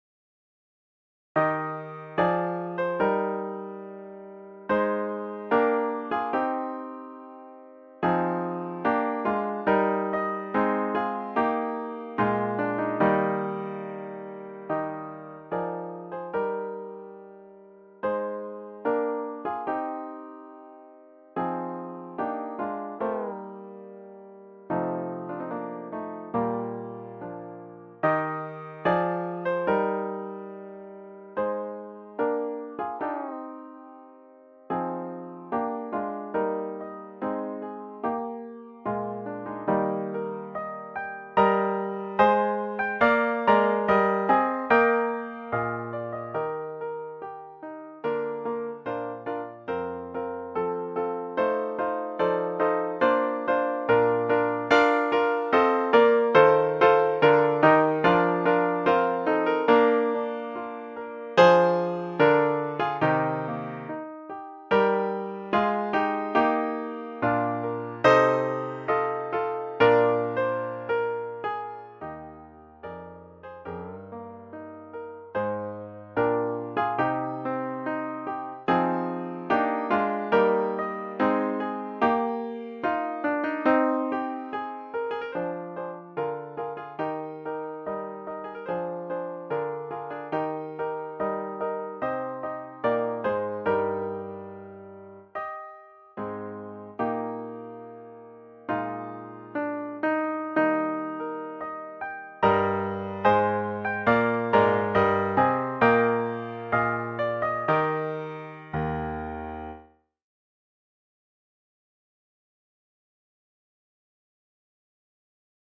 Voicing: SA and Piano